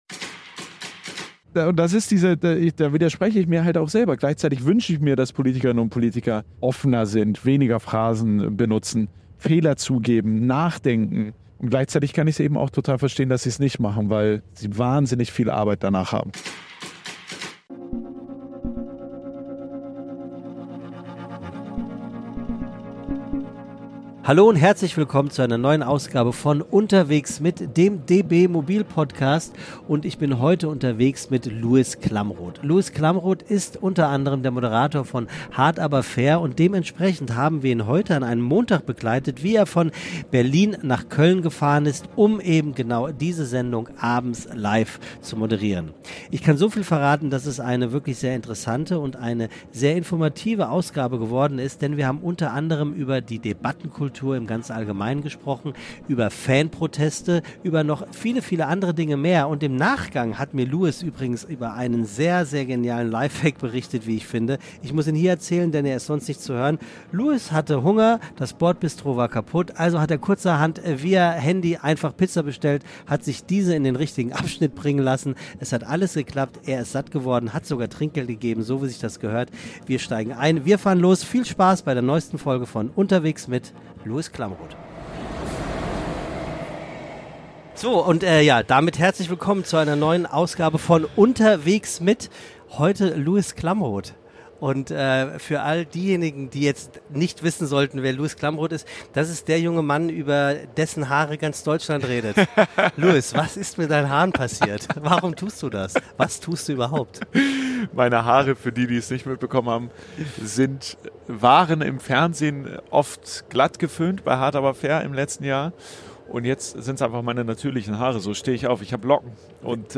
der mit seinem Team zur Produktion seiner Polit-Talkshow „Hart aber Fair“ im ICE von Berlin nach Köln unterwegs ist. Wie diskutiert man denn hart, aber fair?